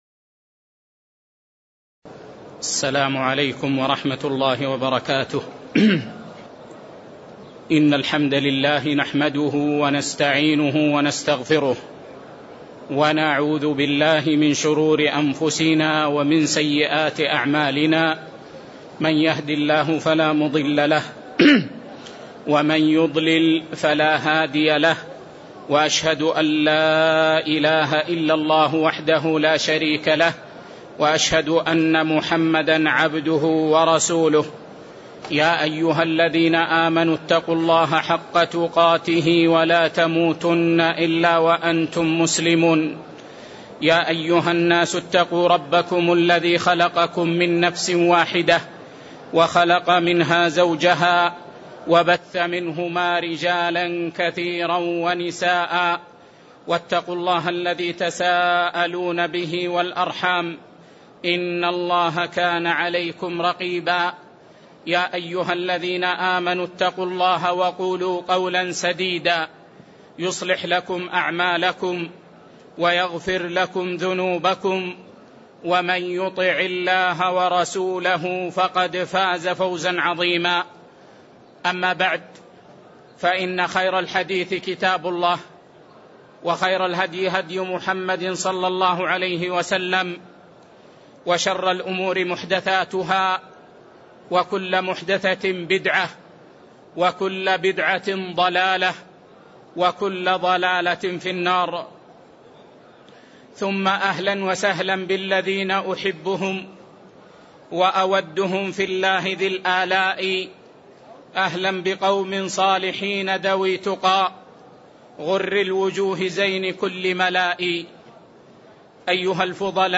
تاريخ النشر ١٠ ذو القعدة ١٤٣٦ هـ المكان: المسجد النبوي الشيخ